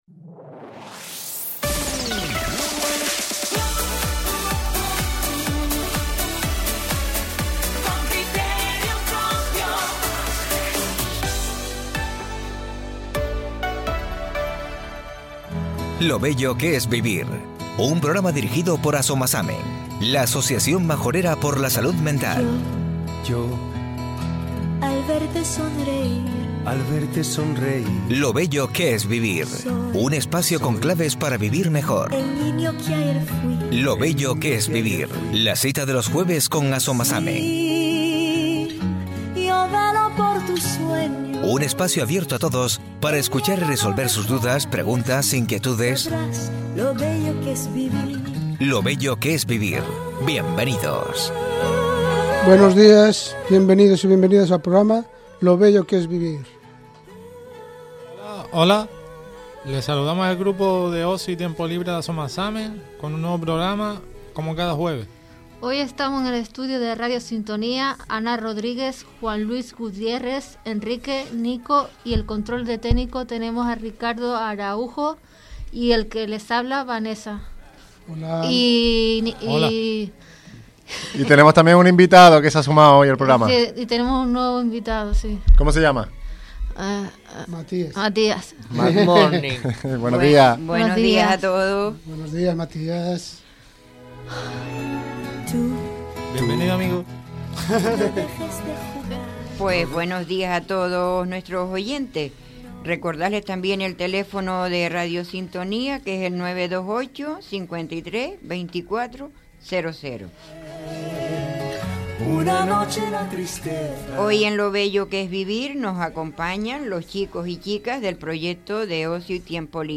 En el espacio Lo Bello que es Vivir nos acompañan, en esta ocasión, los chicos y chicas del proyecto de Ocio y Tiempo Libre “Conectando con la Vida”.